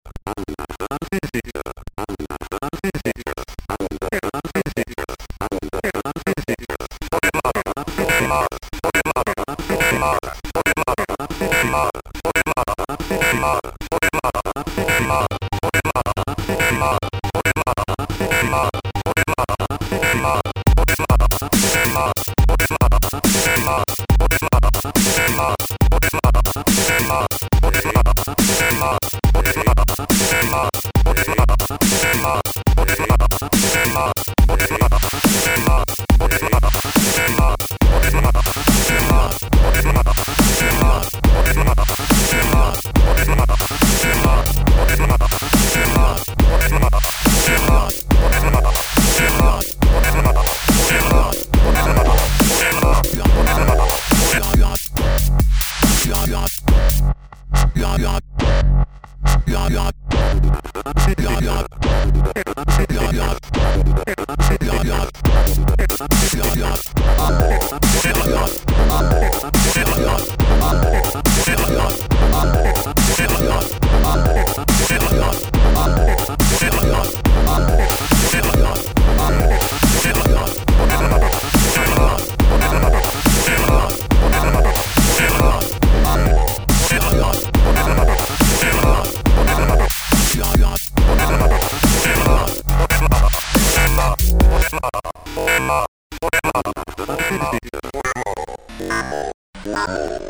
editSPEECH PROCESSED BY OTHER MACHINE I recorded some fun variations about the "another visitor" speech processed by different samplers, effects, vocoders or whatever!
Scratch + sequenced loops Roland DJ70
stay-scratch.mp3